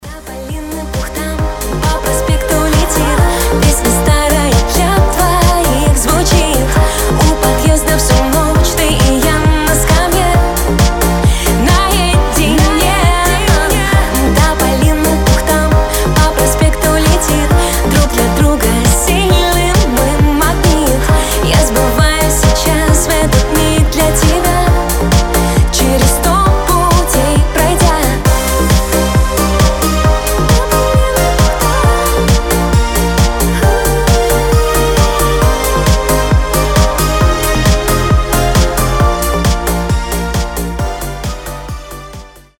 • Качество: 320, Stereo
диско
Synth Pop
женский голос